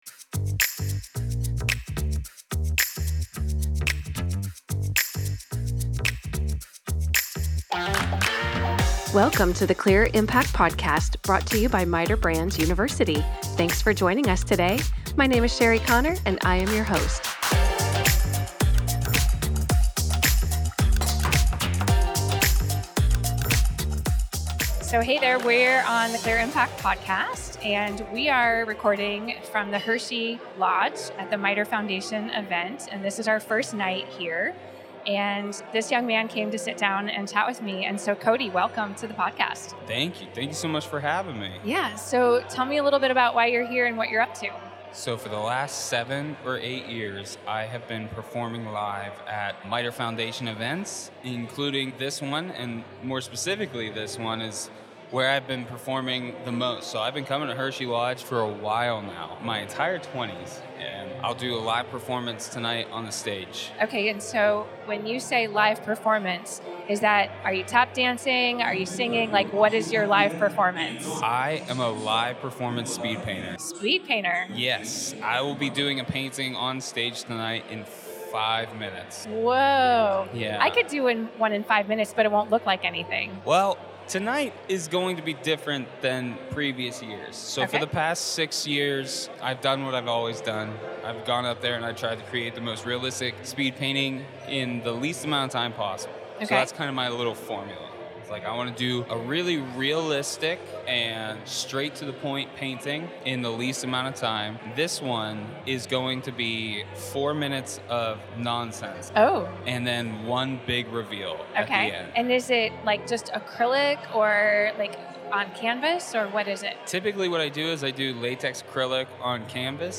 We enjoyed many conversations recently in Hershey, Pennsylvania, at the annual MITER Foundation Golf Tournament and Fundraiser. This fun episode highlights some of the folks that helped from the stage.